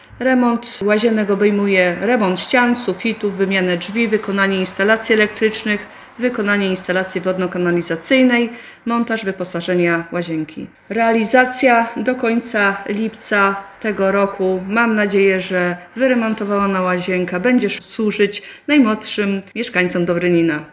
Mówi burmistrz Przecławia, Renata Siembab.